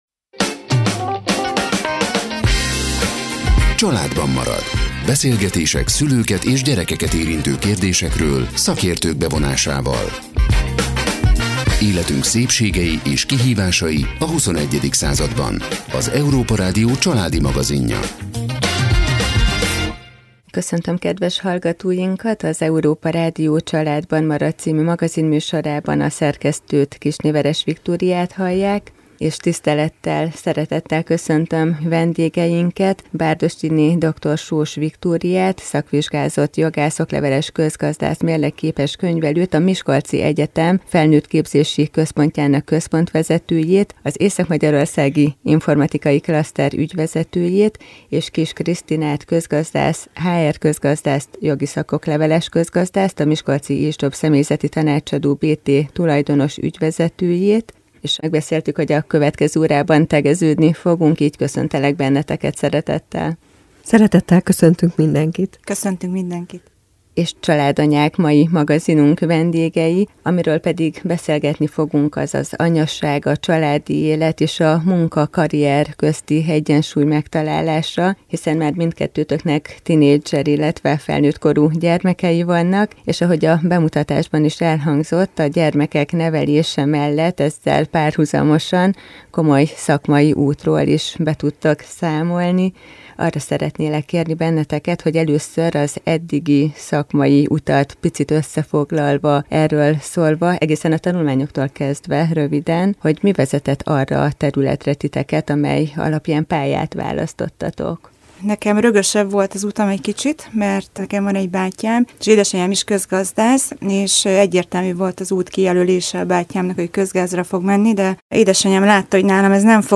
Beszélgetés